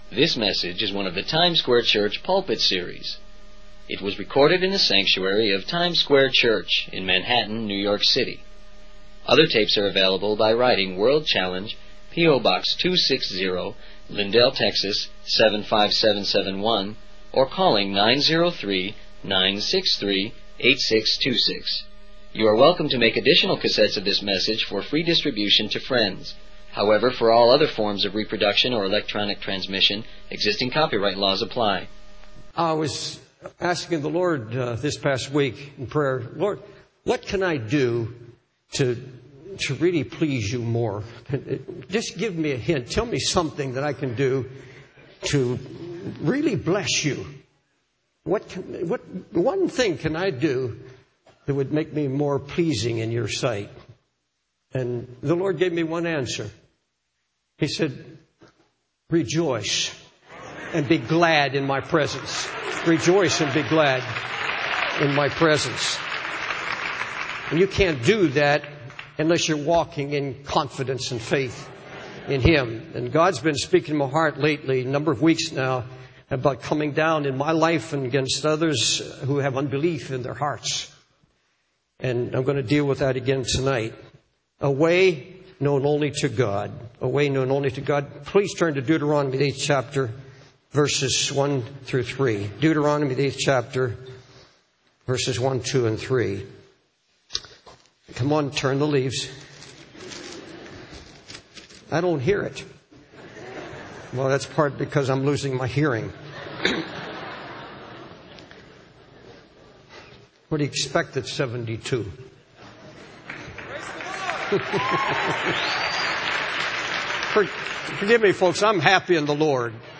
In this sermon, the preacher emphasizes the importance of walking in confidence and faith in God.